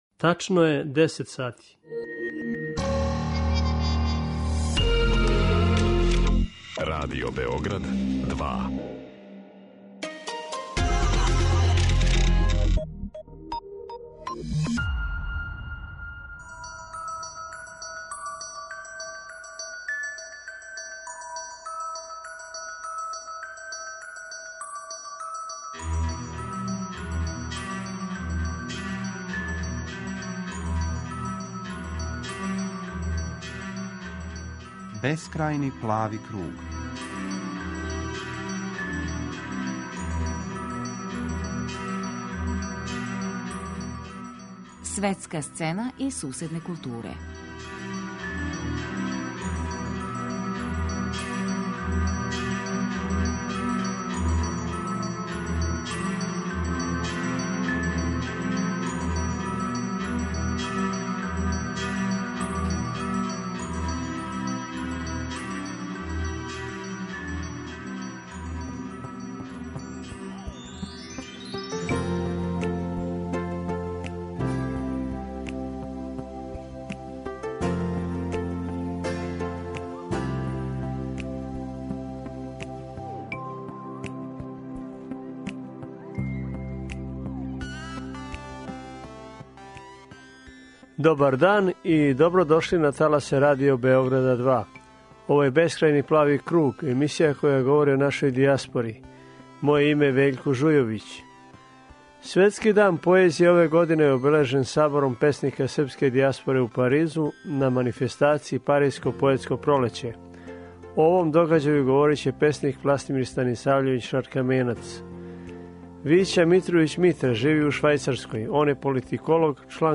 преузми : 20.09 MB Бескрајни плави круг Autor: Група аутора Последња седмична емисија из циклуса СВЕТСКА СЦЕНА И СУСЕДНЕ КУЛТУРЕ посвећена је нашој дијаспори и струјањима између ње и матичне културе у Србији.